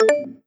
dialog-ok-select.wav